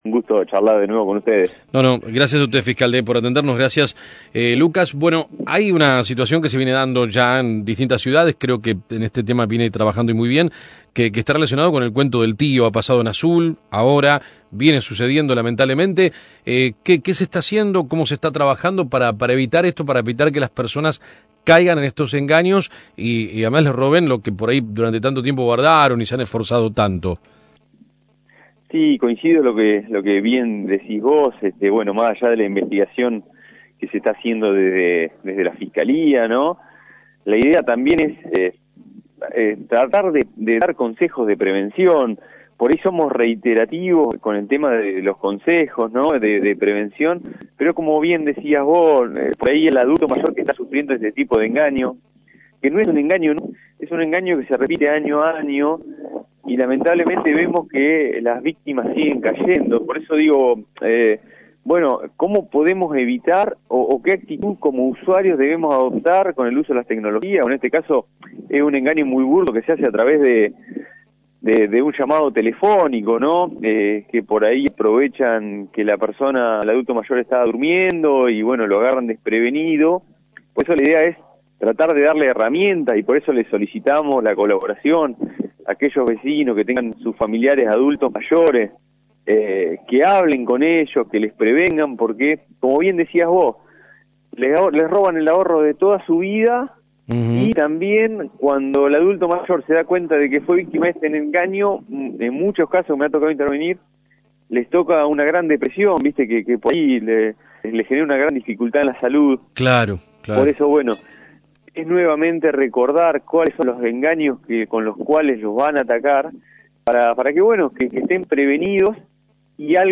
GRAN AUMENTO DE CASOS DE ESTAFAS VIRTUALES Y "CUENTOS DEL TÍO",HABLAMOS CON EL FISCAL LUCAS MOYANO EN AM 1210 - LasFloresDigital